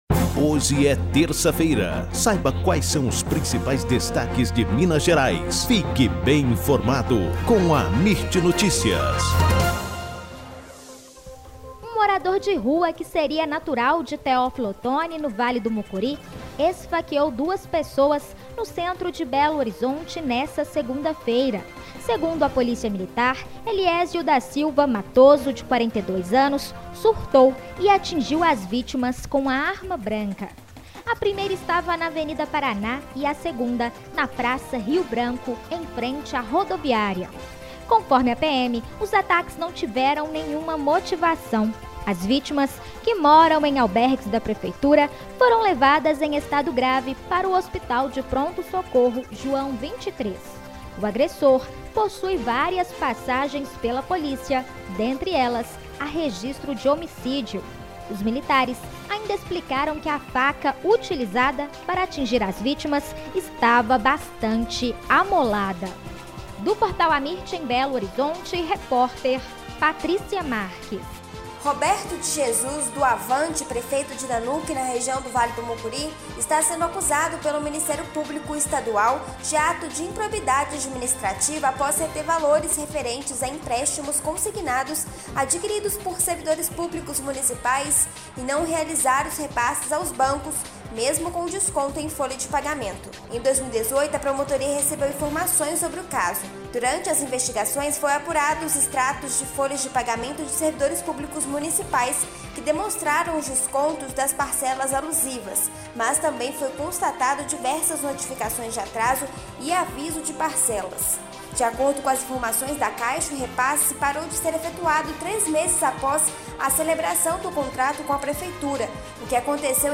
AMIRT Amirt Notícias Destaque Notícias em áudio Rádio e TelevisãoThe estimated reading time is less than a minute